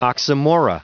Prononciation du mot oxymora en anglais (fichier audio)
Prononciation du mot : oxymora
oxymora.wav